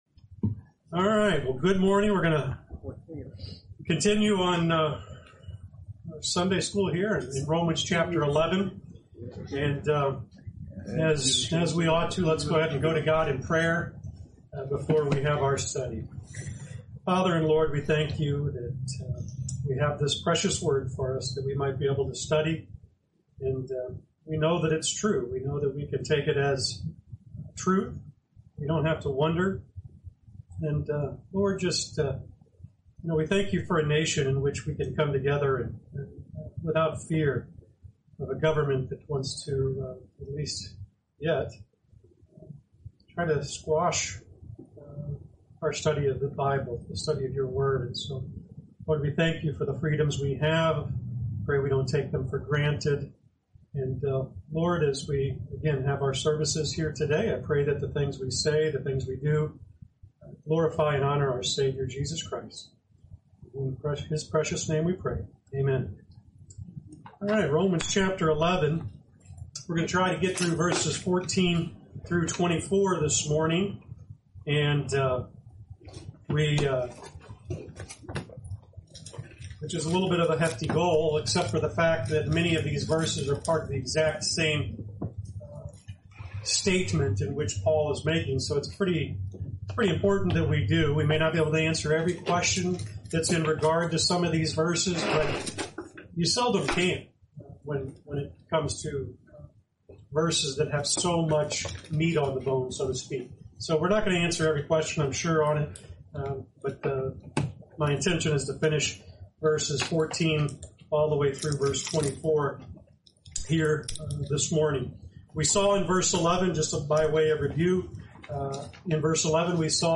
Lesson 72: Romans 11:14-24